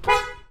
horn_honk.ogg